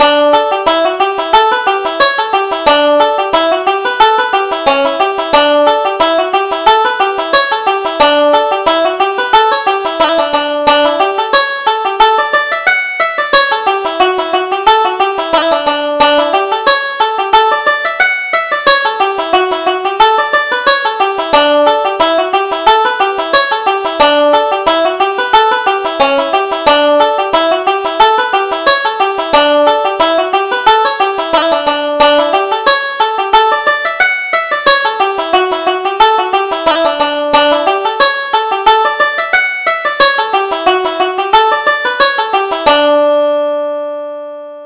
Reel: The Maids of Mitchellstown